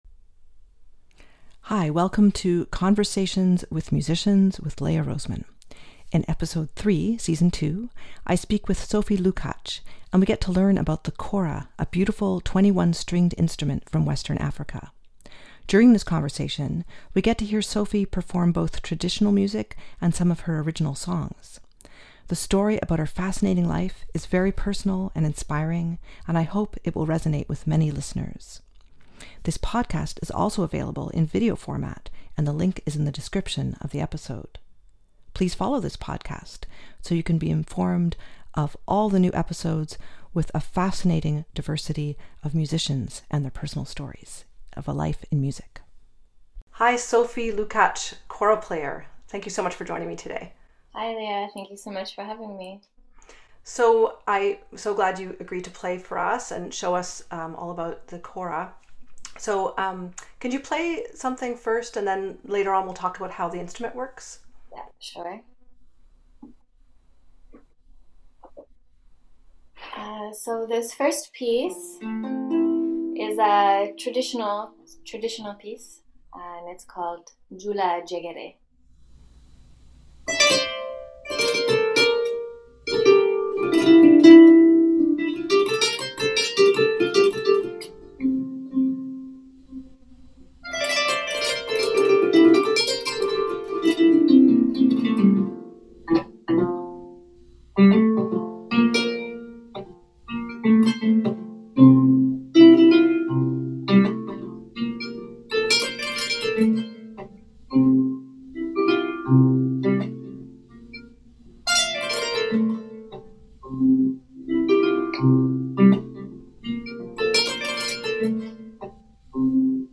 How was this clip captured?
The format for this podcast is in-depth conversations prepared with lots of research and many episodes also feature the guests playing music.